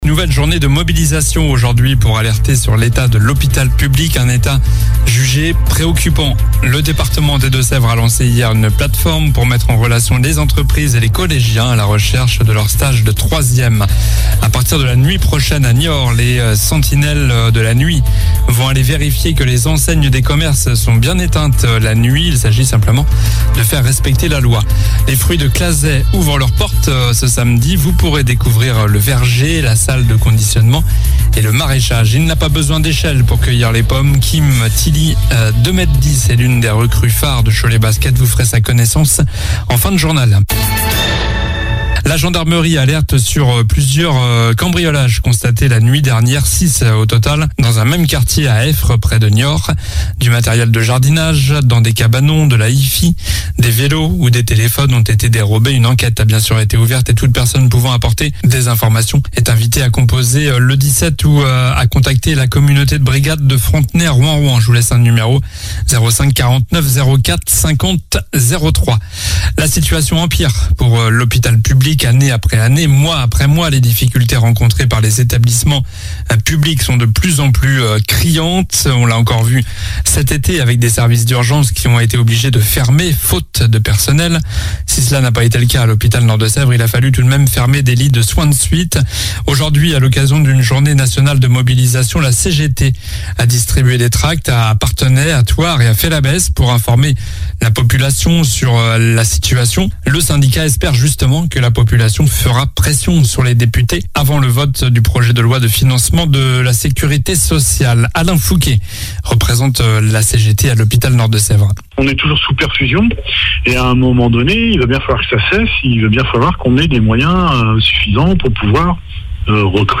JOURNAL DU JEUDI 22 SEPTEMBRE ( soir )